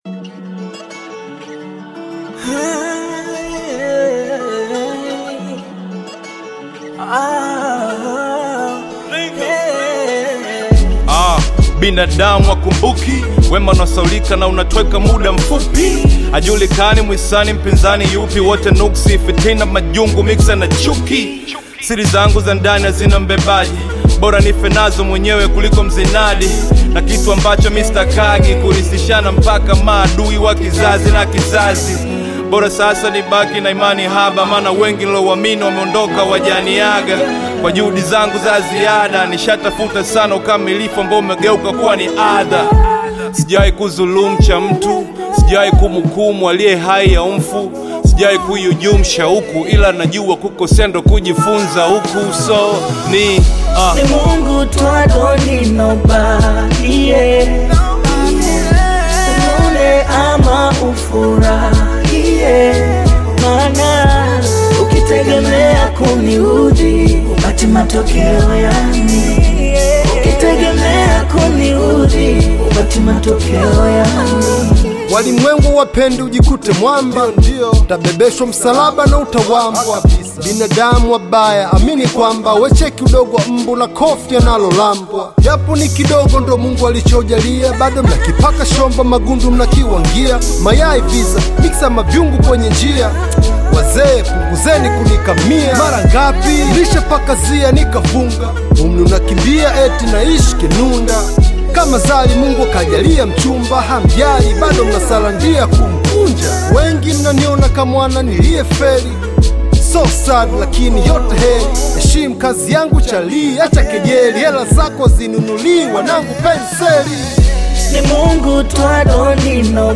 high-energy Afro-Fusion/Bongo Flava collaboration
With its infectious beat and bold delivery